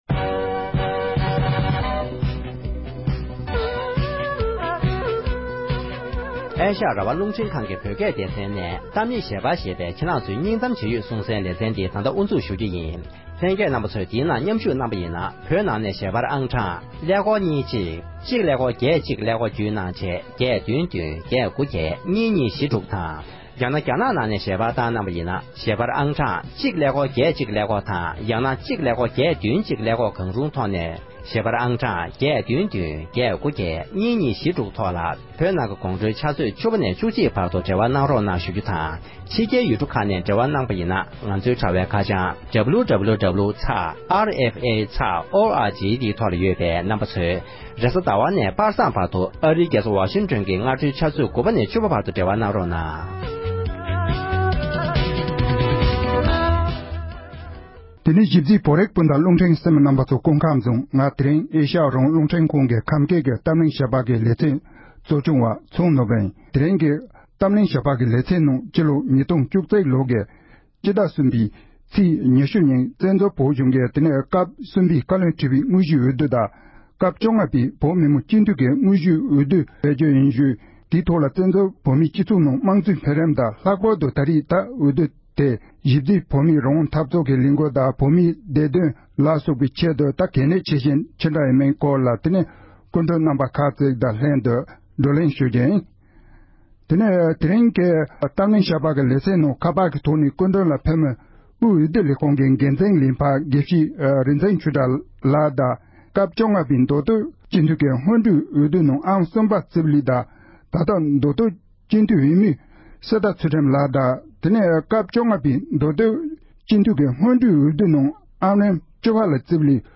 བཙན་བྱོལ་བོད་མིའི་བཀའ་བློན་ཁྲི་པ་དང་བོད་མི་མང་སྤྱི་འཐུས་གཉིས་ཀྱི་འོས་བསྡུའི་ལས་རིམ་སྐོར་འབྲེལ་ཡོད་མི་སྣ་དང་གླེང་མོལ།